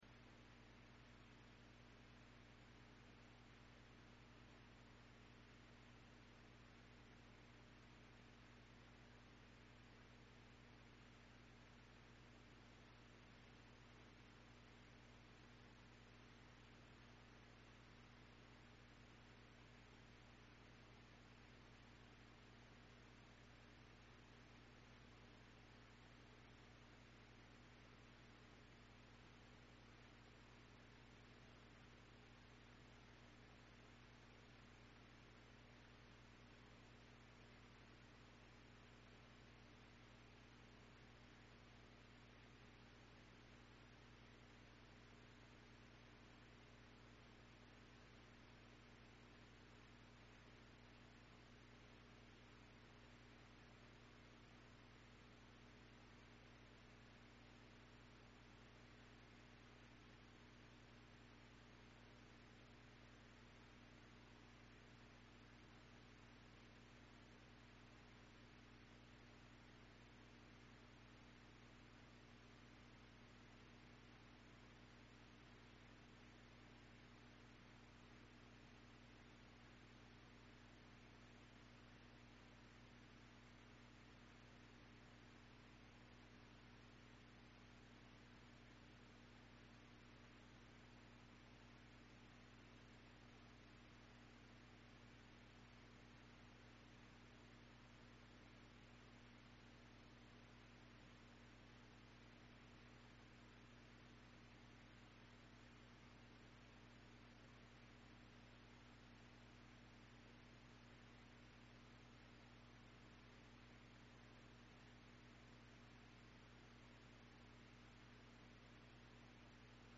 Ephesians 6:1-4 Service Type: Sunday Morning Bible Text